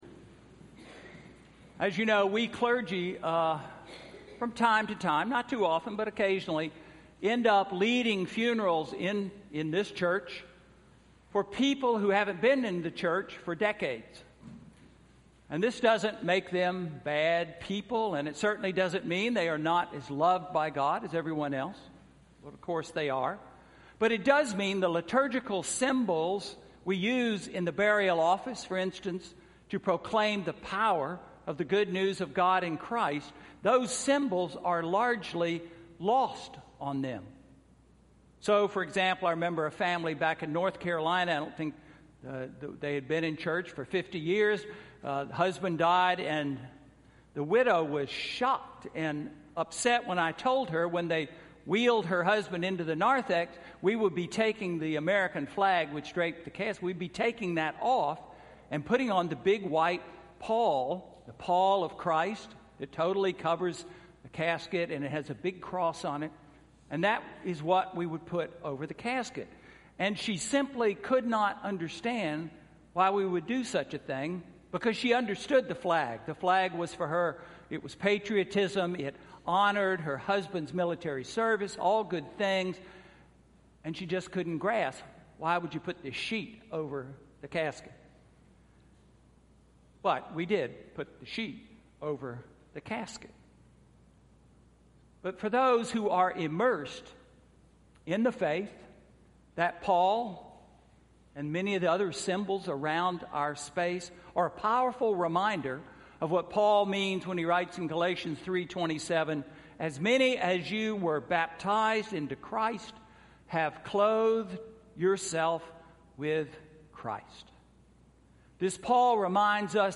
Sermon: Advent One–November 27, 2016